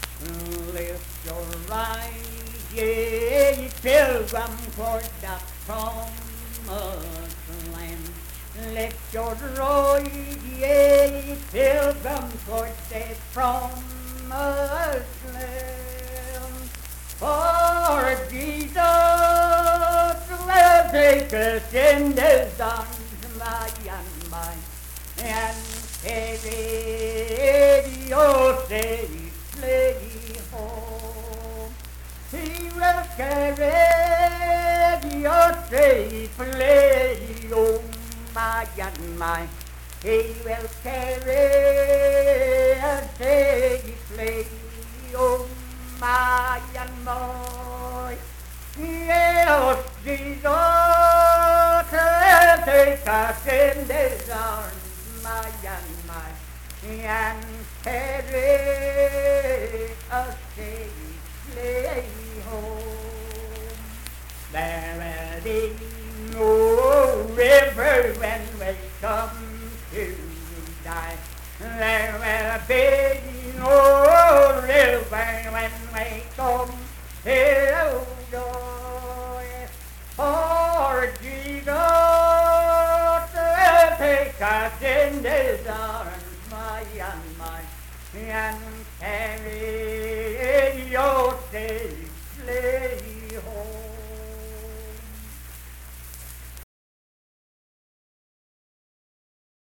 Unaccompanied vocal music performance
Hymns and Spiritual Music
Voice (sung)